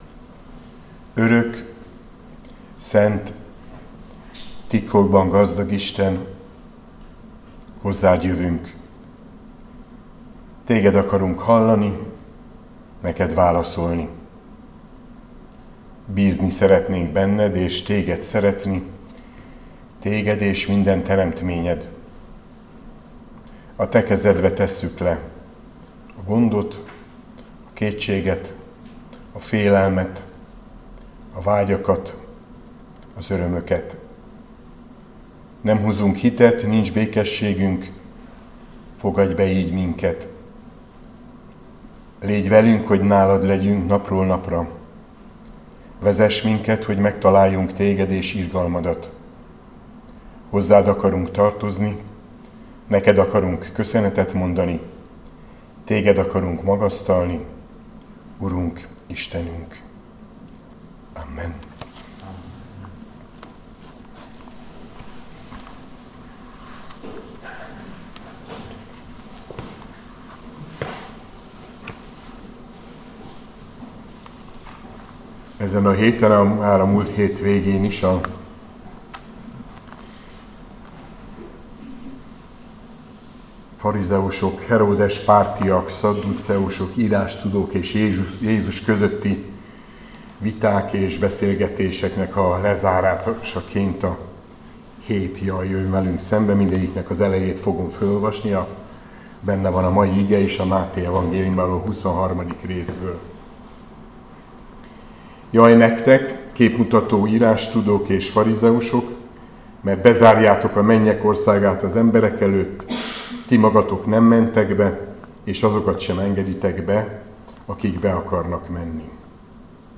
Áhítat, 2024. szeptember 24.
Mt 25,13-36 Balog Zoltán püspök